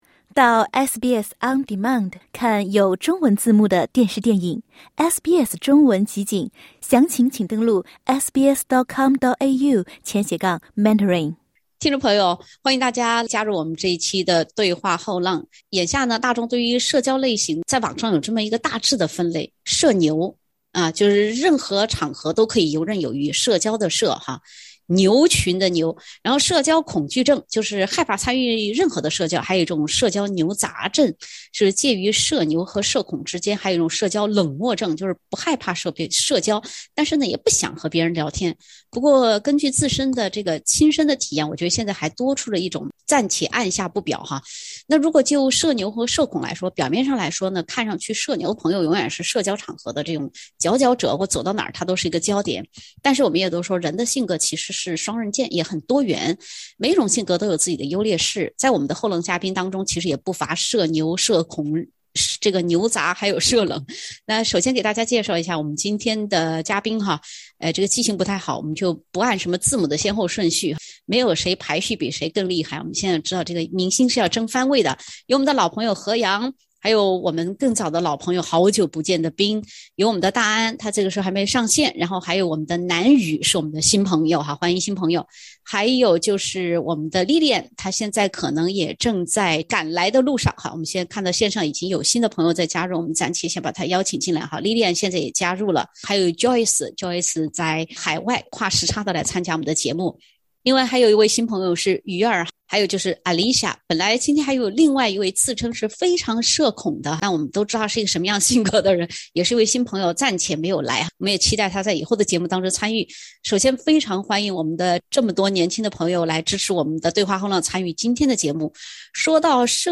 SBS全新谈话类节目《对话后浪》，倾听普通人的烦恼，了解普通人的欢乐，走进普通人的生活。
本期话题：一场疫情咋让“社牛”变“社懒”的。（点击封面图片，收听风趣对话）